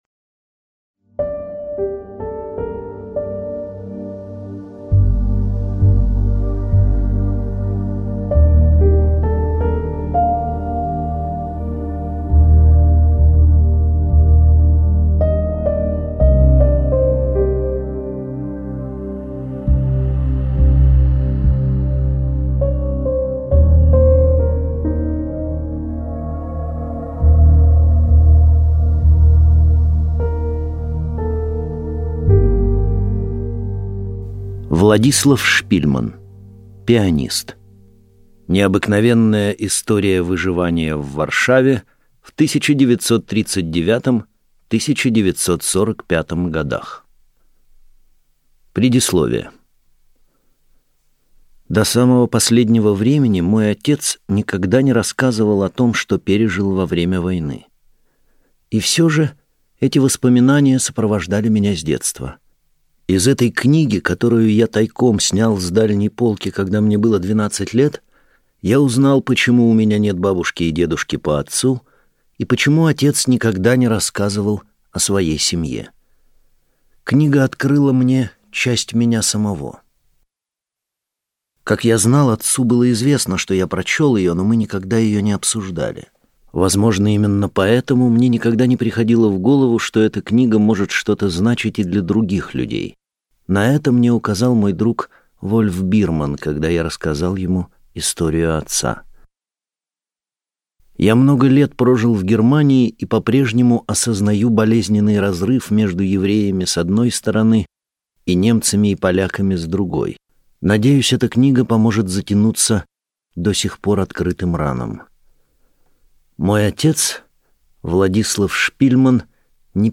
Аудиокнига Пианист | Библиотека аудиокниг